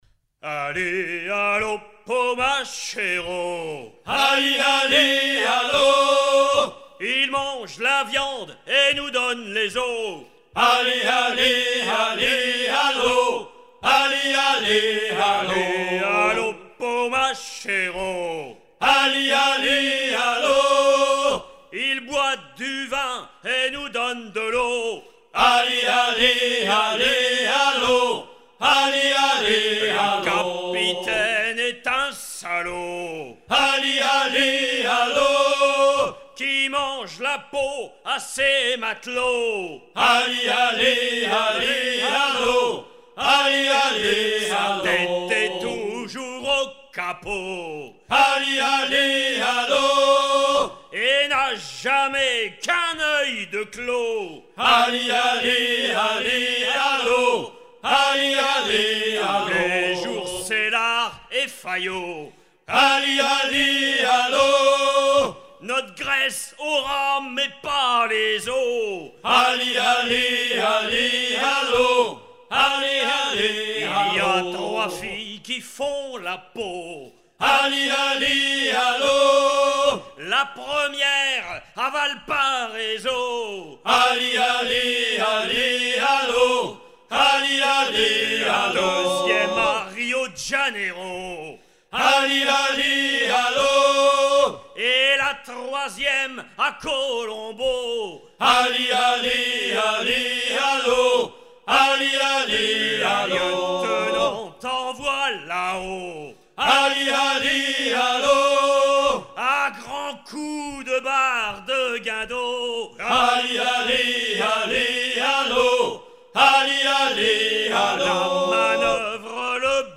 Fonction d'après l'analyste gestuel : à hisser à grands coups
Usage d'après l'analyste circonstance : maritimes
Genre laisse